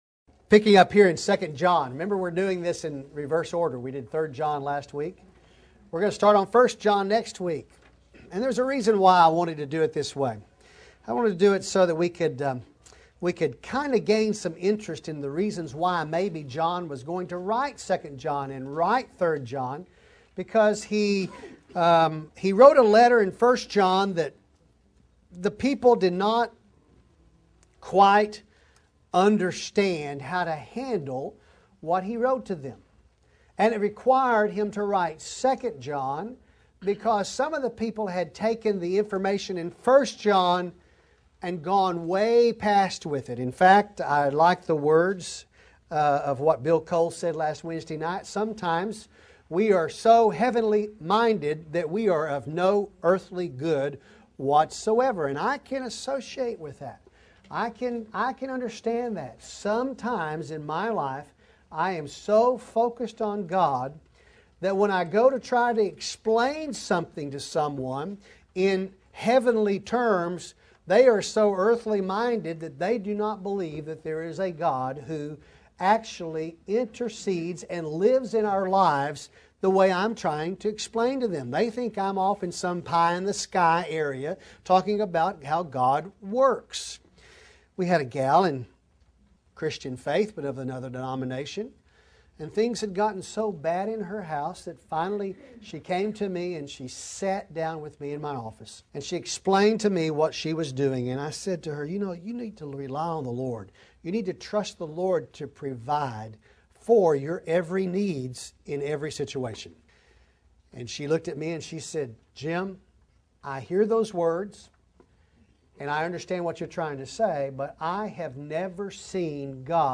2nd John Lesson 1: 2nd John 1:1-1:13 Youtube Vimeo Audio Notes